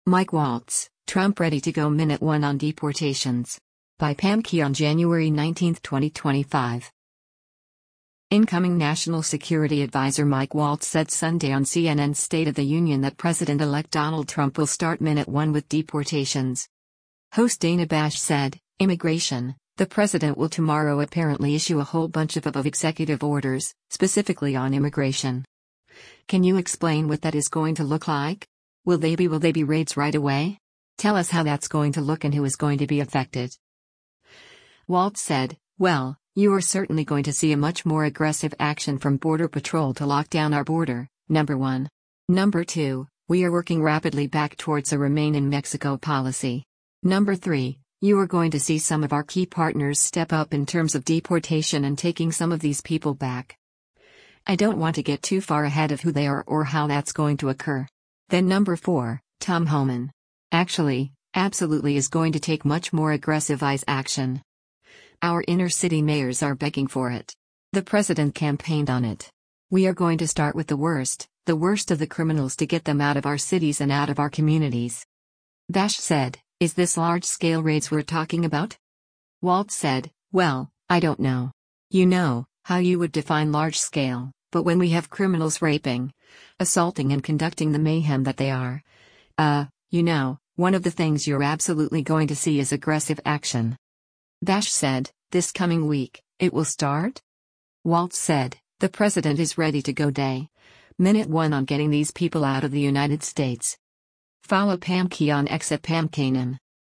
Incoming national security adviser Mike Waltz said Sunday on CNN’s “State of the Union” that President-elect Donald Trump will start “minute one” with deportations.